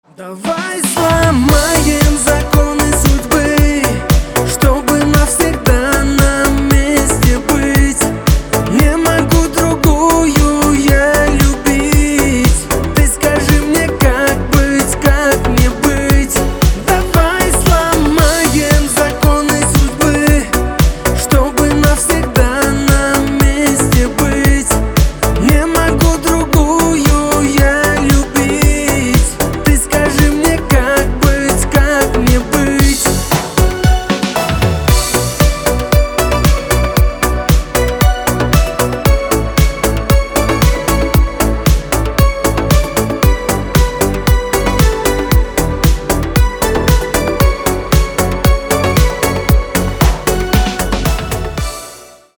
• Качество: 320, Stereo
поп
мужской вокал